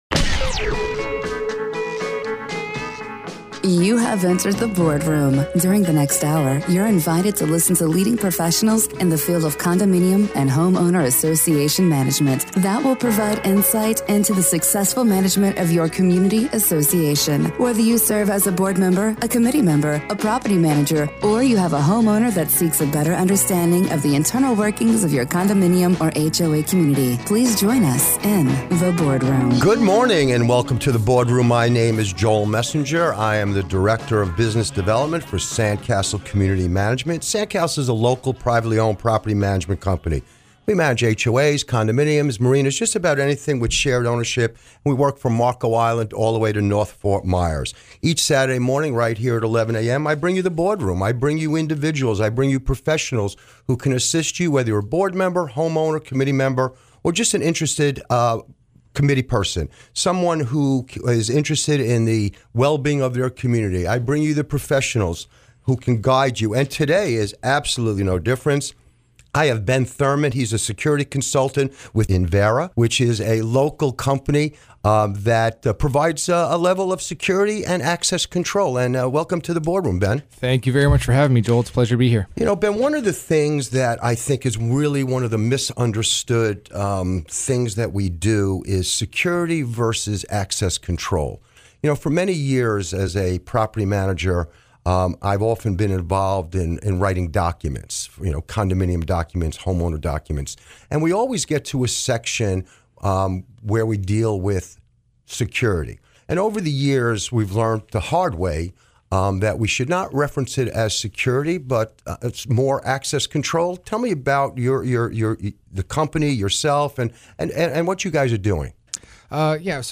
Hear Envera experts discuss community security, access control, and virtual guarding on the Board Room radio show.